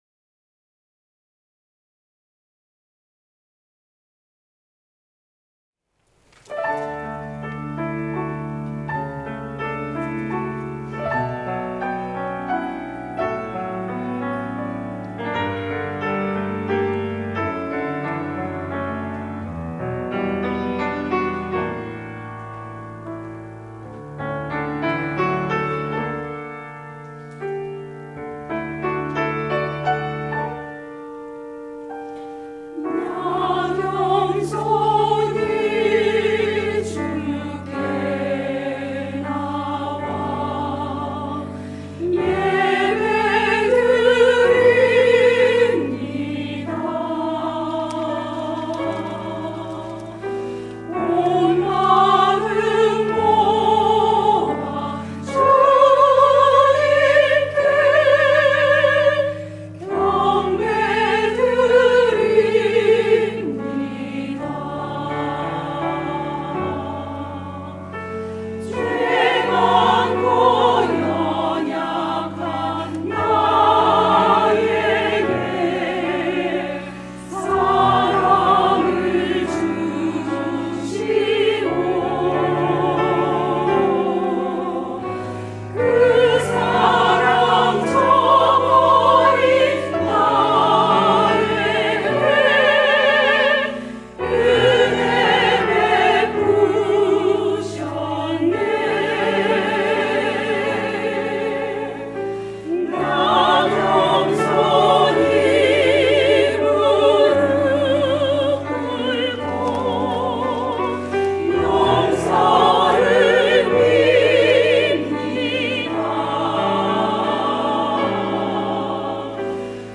샤론